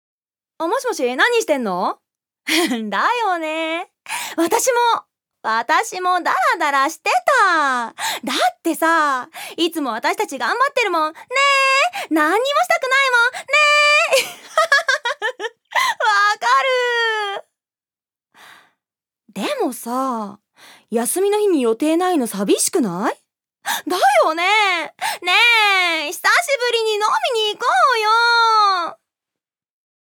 預かり：女性
セリフ１